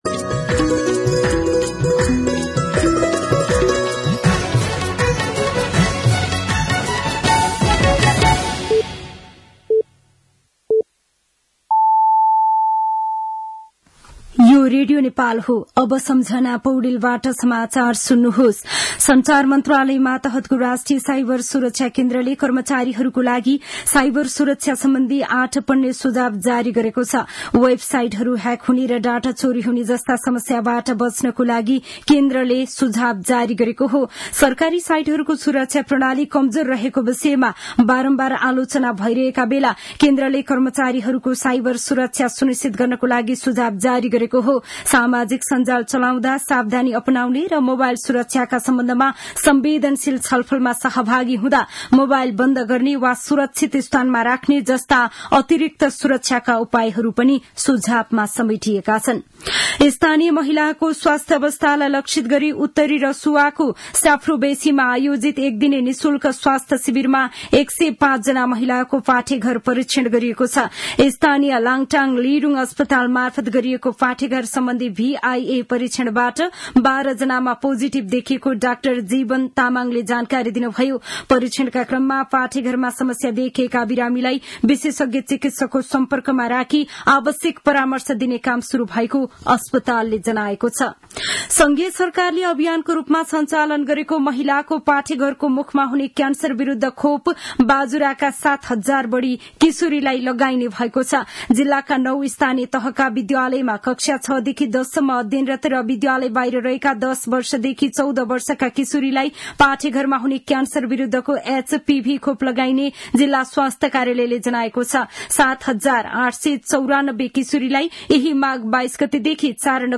दिउँसो ४ बजेको नेपाली समाचार : १४ माघ , २०८१
4-pm-news-1.mp3